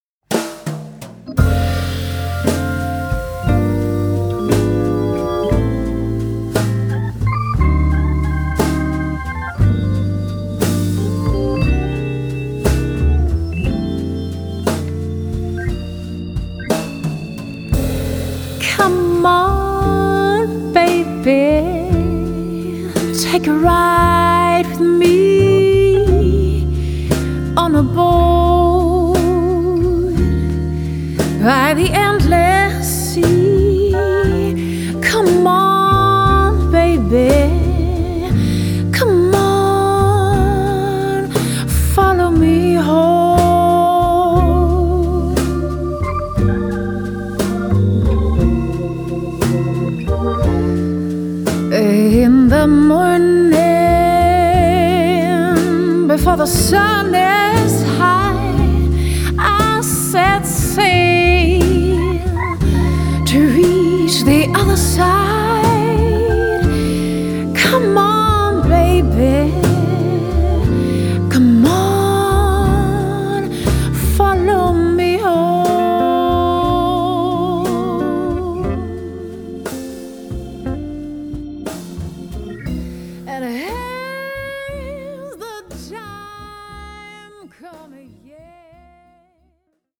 Vocals
Bass
Electric and acoustic guitars
Acoustic and Lap Steel guitar
Piano, Organ, Wurlitzer
Drums and percussion